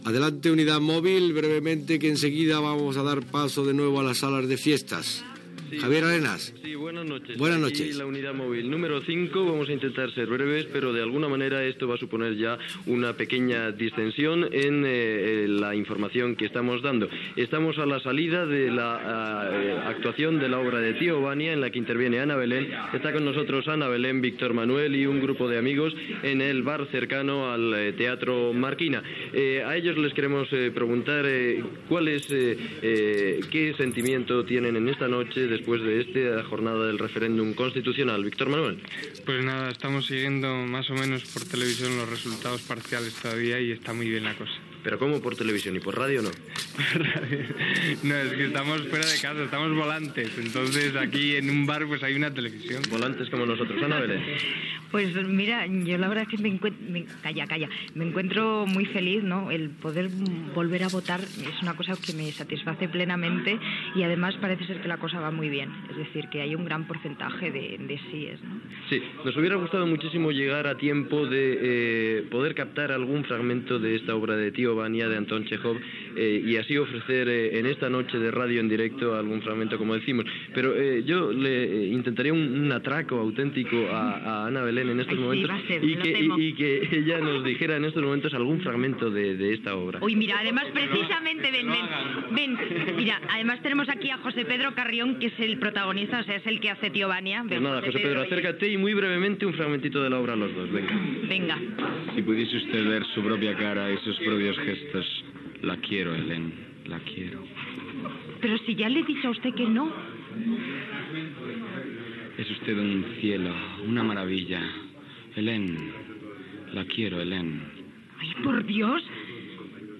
Connexió amb la unitat mòbil, que està al Teatro Marquina de Madrid, per entrevistar a Ana Belén i Víctor Manuel la matinada del dia següent del referèndum per aprovar la Constitució espanyola
Informatiu